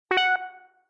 positive-beep.mp3